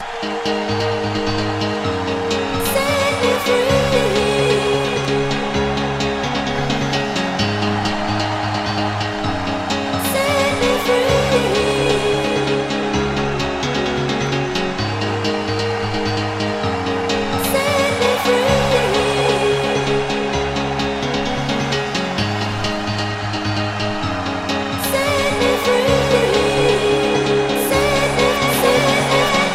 Rap_Setmefree.mp3